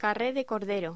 Locución: Carré de cordero